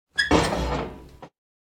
open-door.ogg.mp3